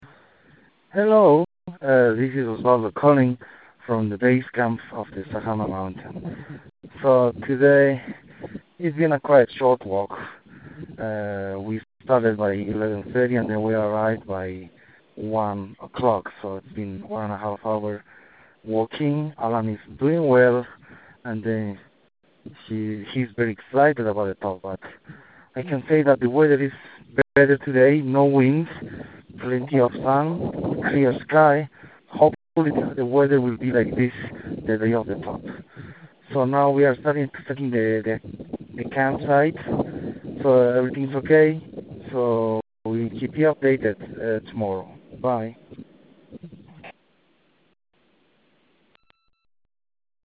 Bolivia Everest Prep Climb Dispatch